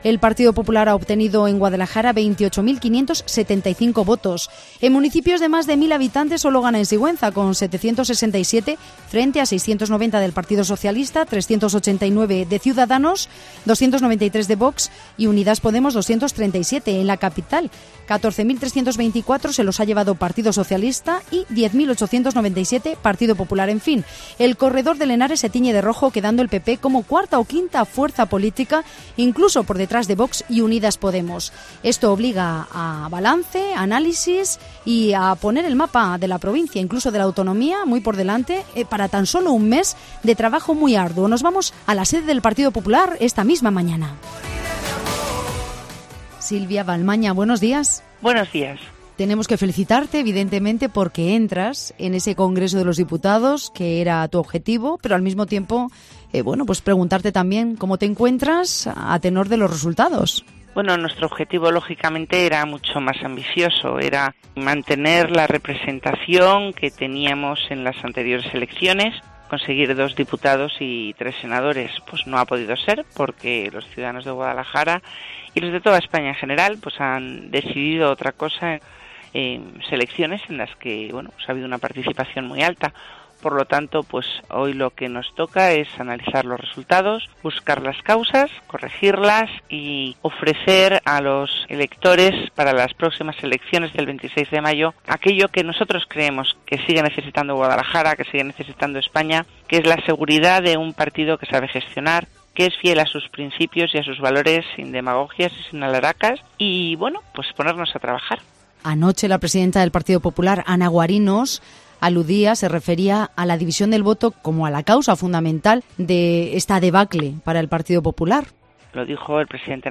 Silvia Valmañadiputada electa del Partido Popular en el Congreso de los Diputados por la provincia de Guadalajara, ha realizado, en los micrófonos de Cope Guadalajara, su análisis y balance de los resultados de las Elecciones Generales, celebradas ayer domingo 28 de abril.